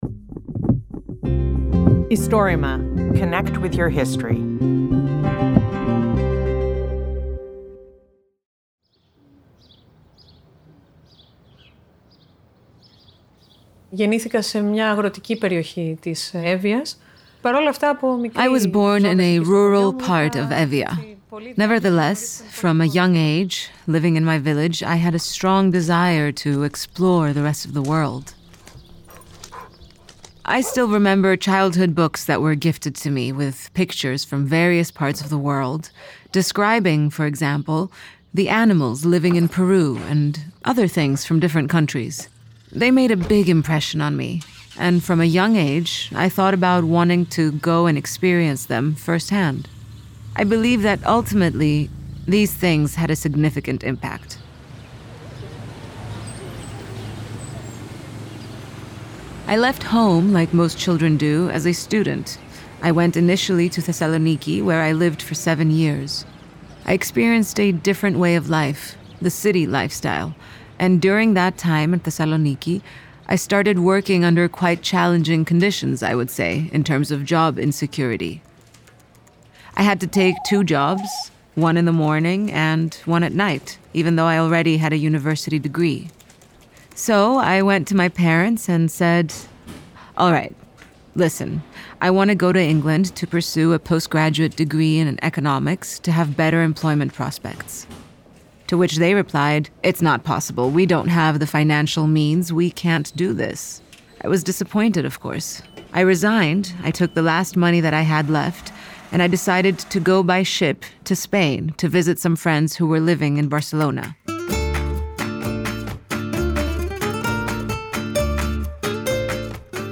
Istorima is the bigest project of recording and preserving oral histories of Greece.